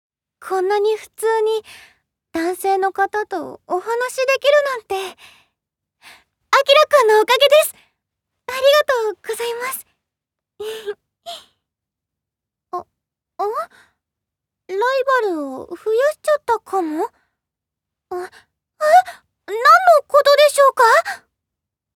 ボイスサンプル
清楚な学生